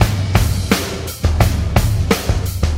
• 115 Bpm Breakbeat G Key.wav
Free breakbeat sample - kick tuned to the G note. Loudest frequency: 1422Hz
115-bpm-breakbeat-g-key-N6X.wav